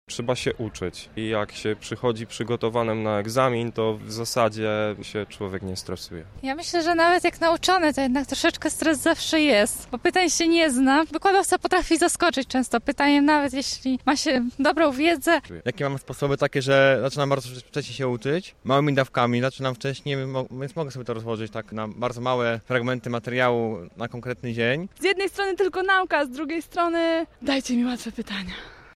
O to zapytaliśmy lubelskich żaków: